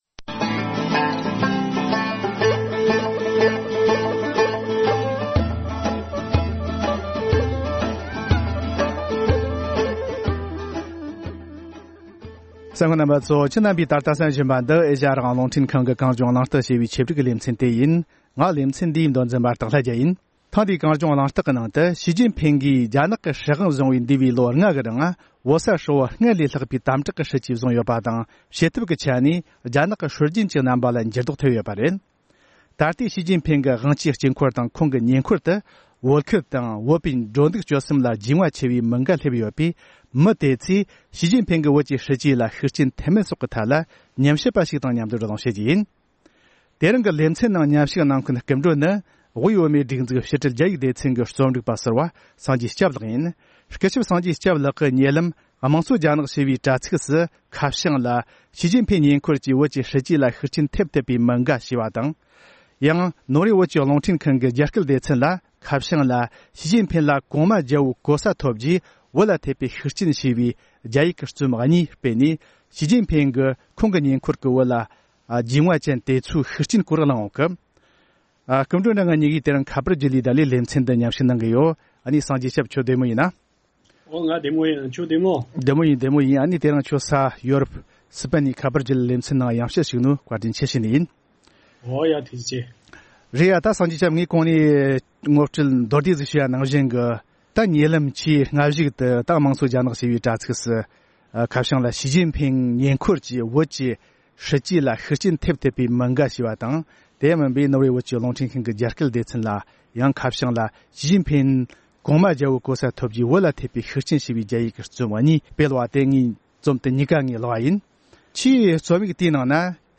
རྒྱ་ནག་གི་སྲིད་འཛིན་ཞི་ཅིན་ཕིང་གིས་བོད་ཐོག་འཛིན་པའི་སྲིད་བྱུས་ལ་བོད་སྐོར་རྒྱུས་མངའ་ཡོད་པའི་དཔོན་རིགས་ཁག་གི་ཤུགས་རྐྱེན་ཡོད་མེད་ཐད་གླེང་མོལ།